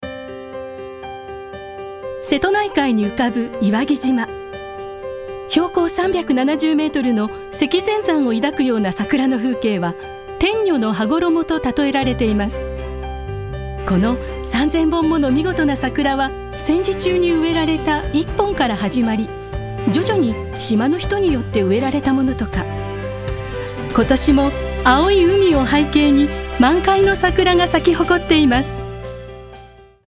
声を聴く Voice Sample